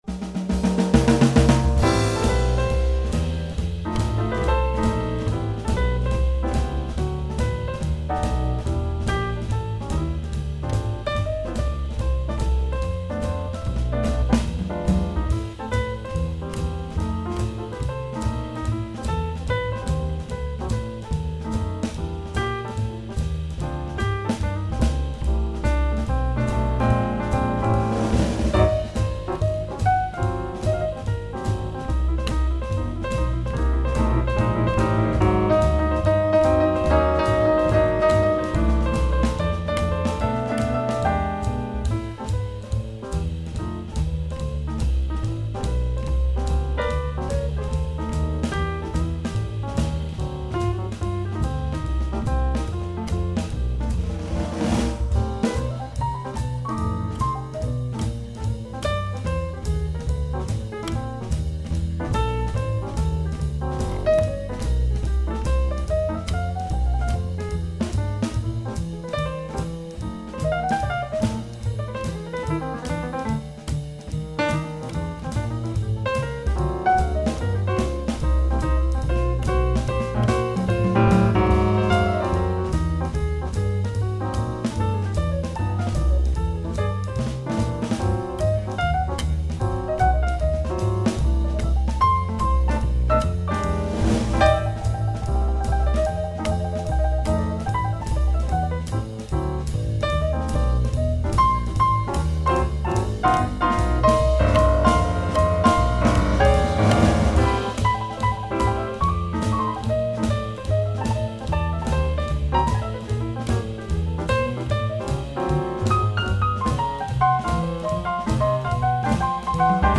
Centre Georges Brassens Avrillé en concert
New-Orleans Classics Revisited
piano et arrangements
contrebasse et chant
batterie
saxophone et chant